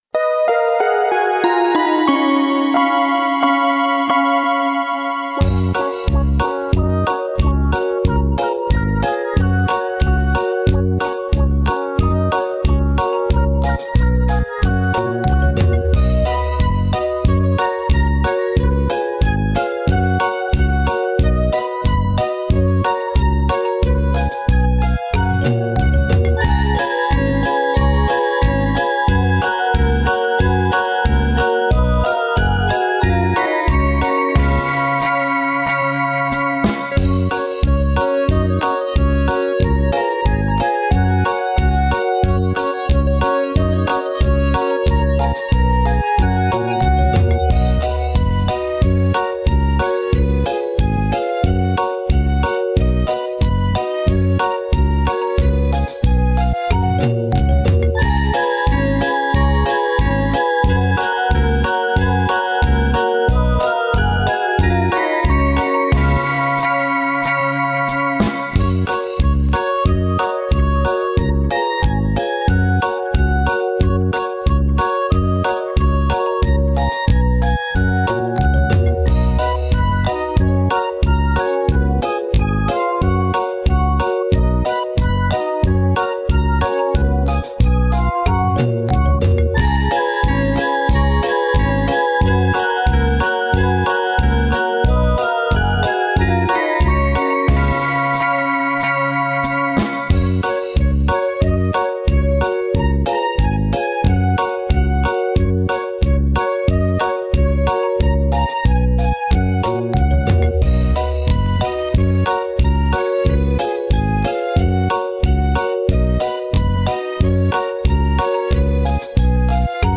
Скачать минус: